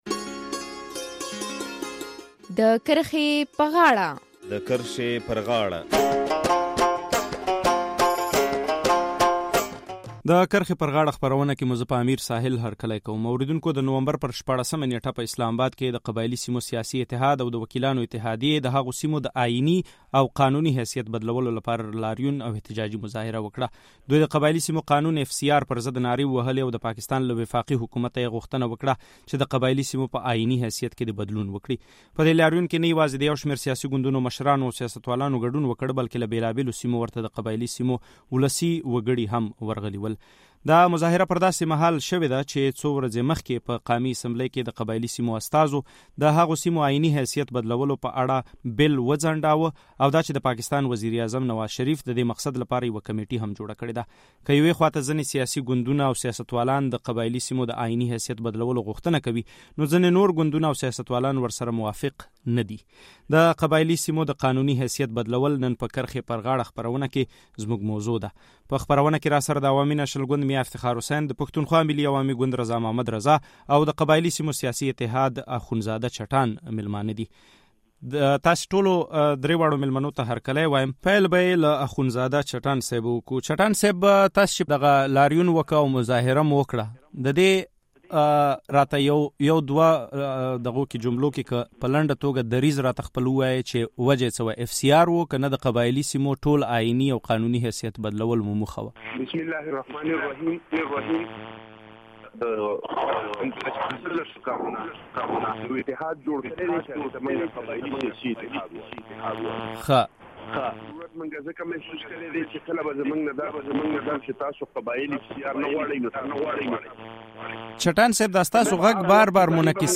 د نومبر پر ۱۶مه نېټه په اسلام اباد کې د قبایلي سیمو سیاسي اتحاد او د وکیلانو اتحادیې د هغو سیمو د اییني او قانوني حیثیت بدلولو لپاره لاریون او احتجاجي مظاهره وکړه. همدا موضوع په خپرونه کې څېړو چې پکې د عوامي نیشنل ګوند میا افتخار حسین، د پښتونخوا ملي عوامي ګوند رضا محمد رضا او د قبایلي سیمو سیاسي اتحاد او پیپلز ګوند اخونزاده چټان راسره مېلمانه دي.